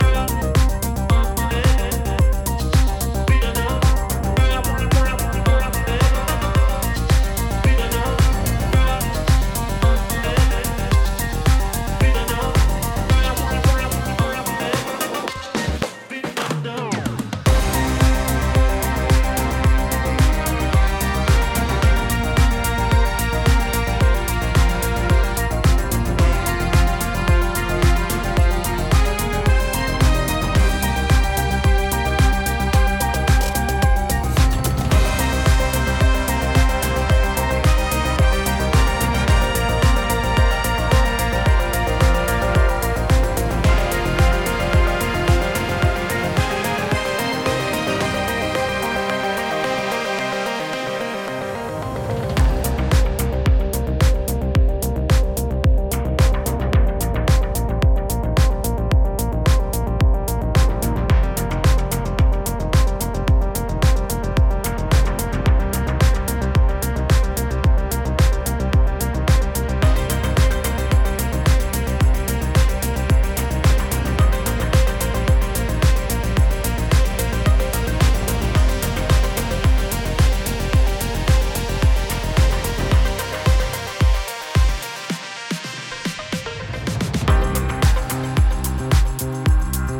ジャンル(スタイル) HOUSE / INDIE DANCE / NU DISCO